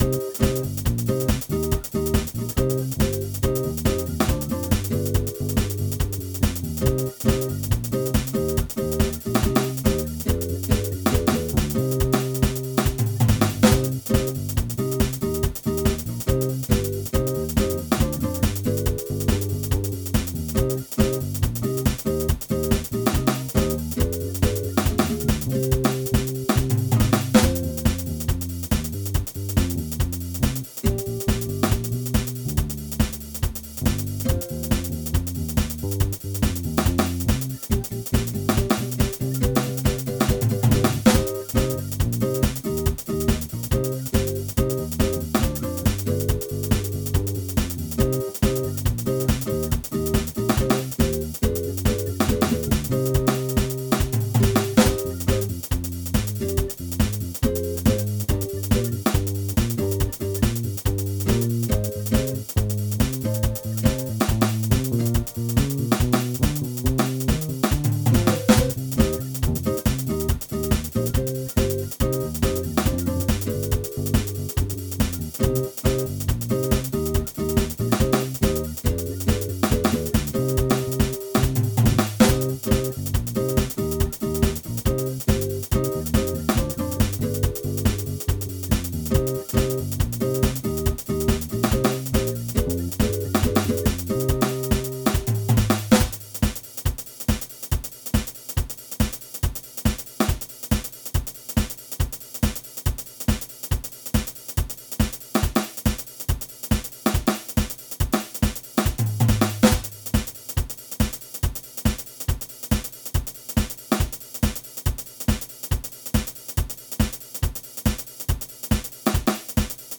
This is an example where I just turned on the drum machine and started improvising (I added the bass later).  I kind of go off the rails at about the 1:00 mark, but that is what happens.